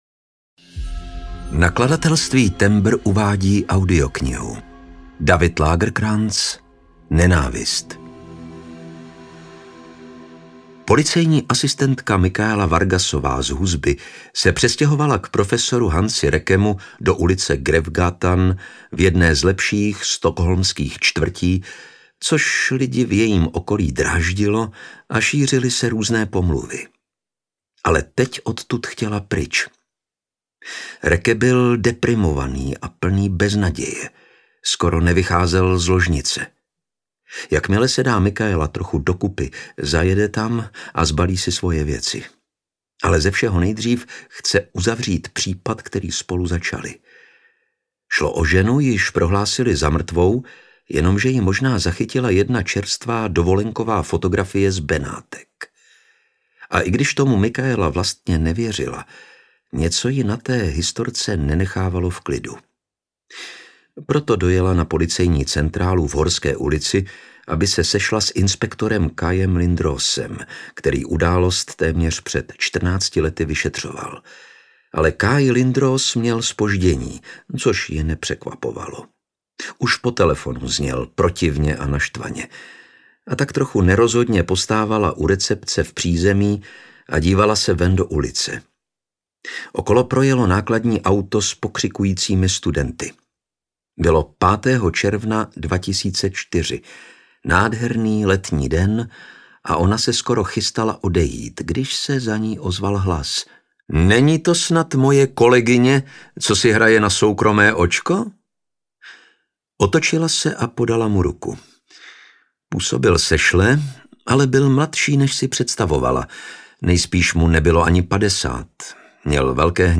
Nenávist audiokniha
Ukázka z knihy
• InterpretLukáš Hlavica